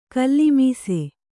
♪ kallimīse